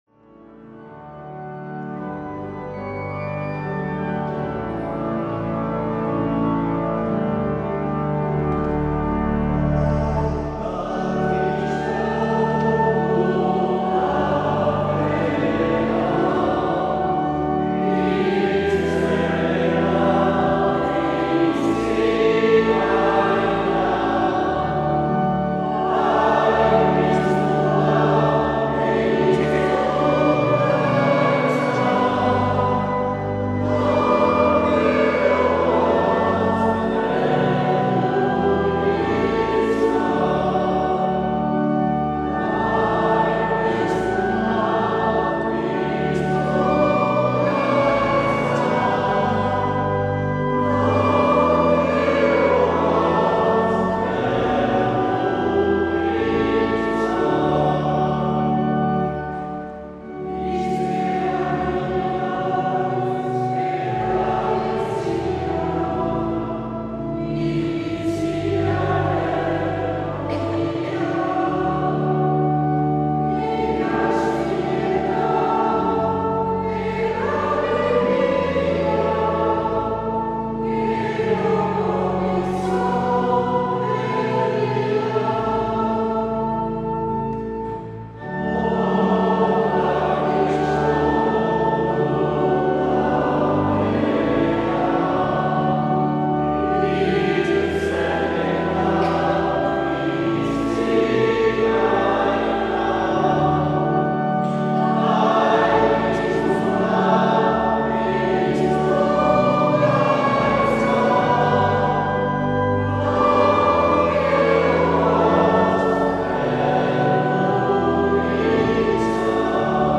Meza